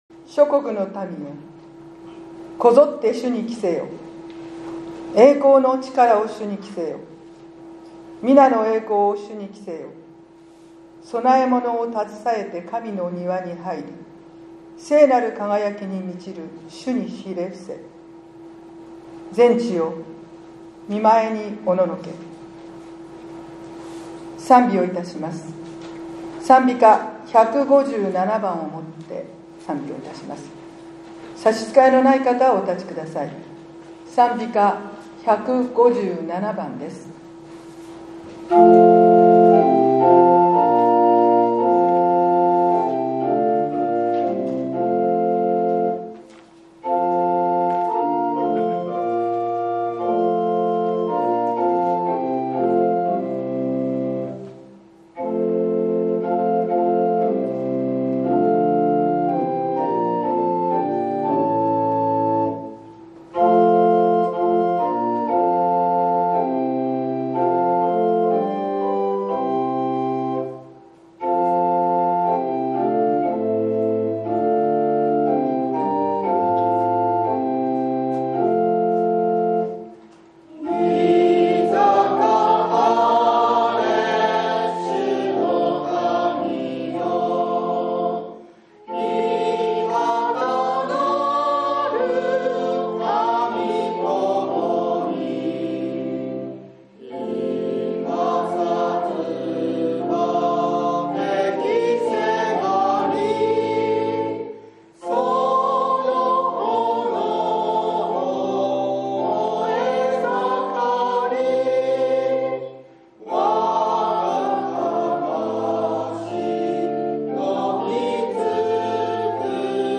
１０月１９日（日）主日礼拝